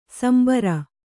♪ sambara